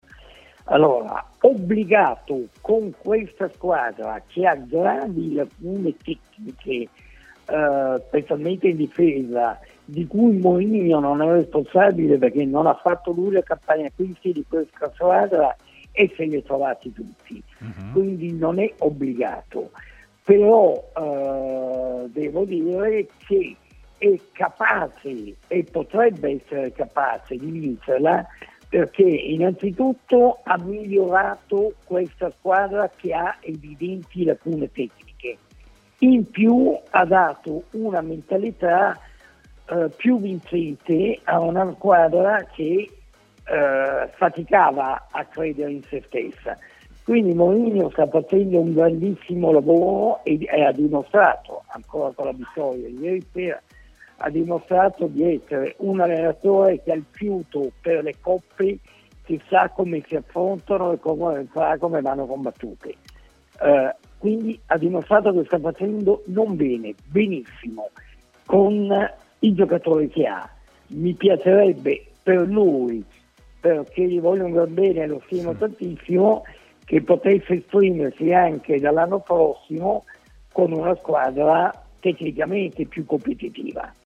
Intervenuto a Stadio Aperto, trasmissione in onda su TMW Radio